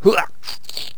stickfighter_attack3.wav